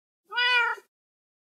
kot iz mainkrafta Meme Sound Effect
Category: Games Soundboard